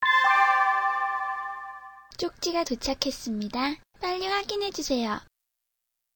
팝코 쪽지 수신음